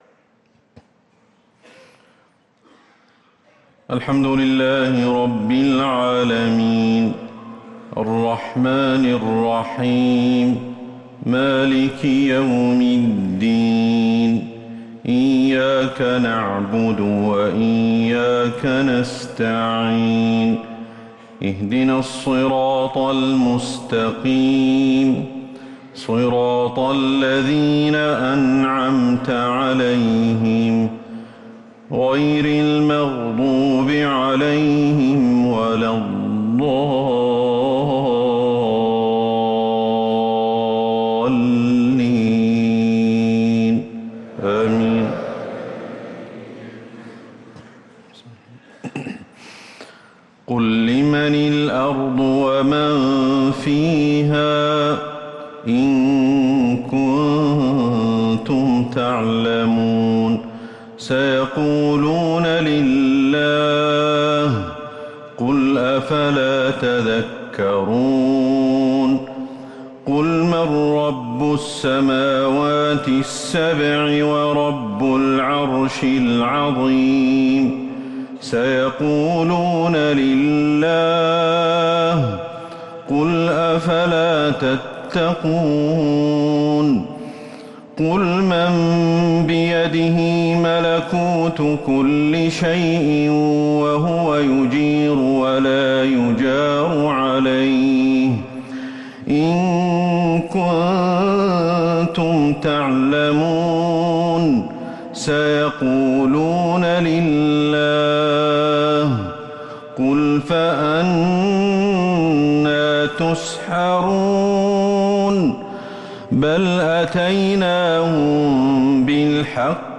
صلاة الفجر للقارئ أحمد الحذيفي 20 ذو الحجة 1444 هـ
تِلَاوَات الْحَرَمَيْن .